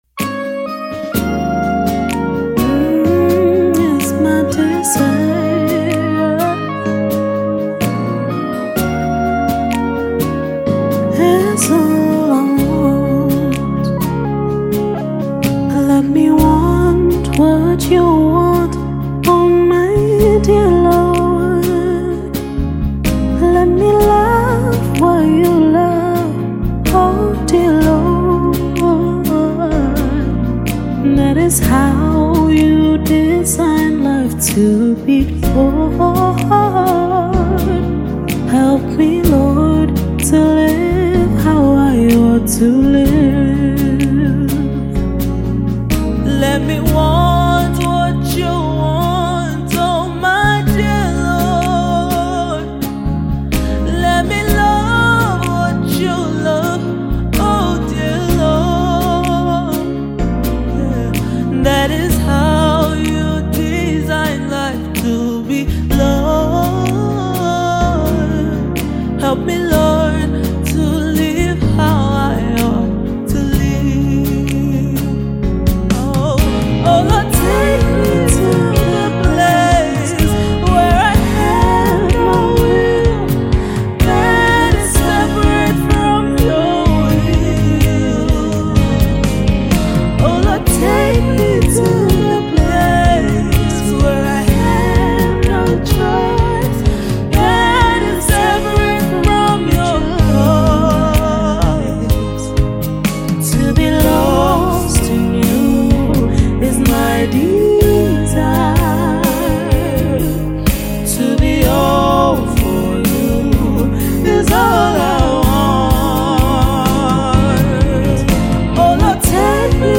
African Gospel
live performance